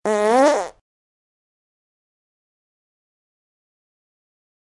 Fart Noise Sound Effects Soundboard: Play Instant Sound Effect Button